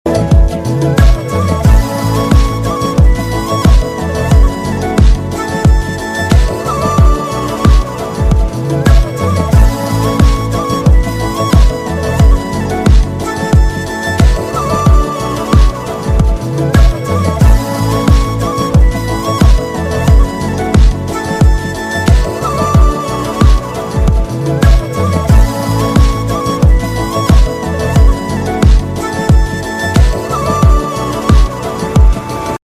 6TypeInstrumental / Background Music Tone